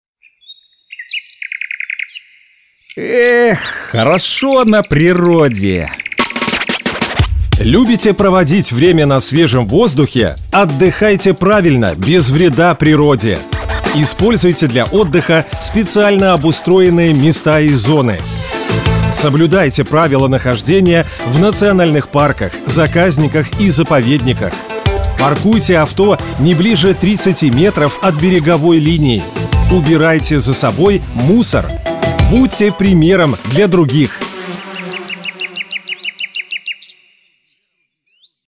№-30_-Минприроды_Отдых-на-природе_Радио.mp3